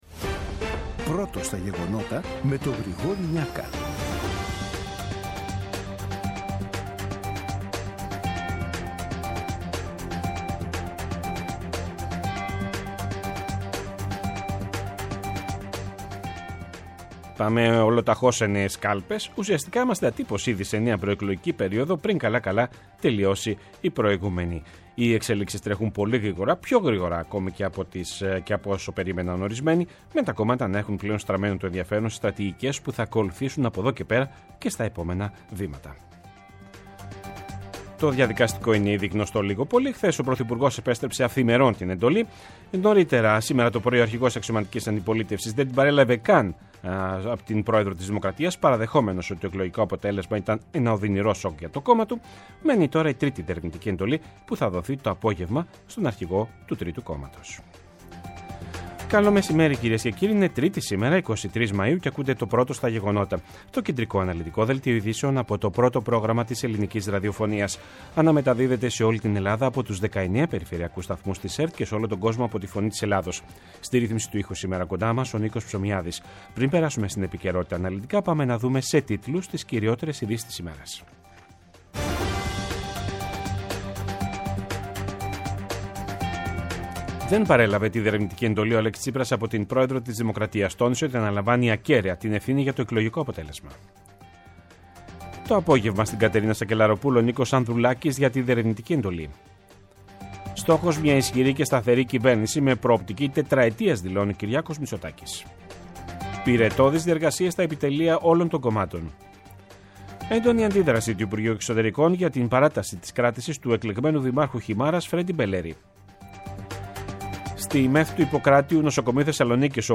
Το κεντρικό ενημερωτικό μαγκαζίνο του Α΄ Προγράμματος, από Δευτέρα έως Παρασκευή στις 14.00. Με το μεγαλύτερο δίκτυο ανταποκριτών σε όλη τη χώρα, αναλυτικά ρεπορτάζ και συνεντεύξεις επικαιρότητας. Ψύχραιμη ενημέρωση, έγκυρη και έγκαιρη.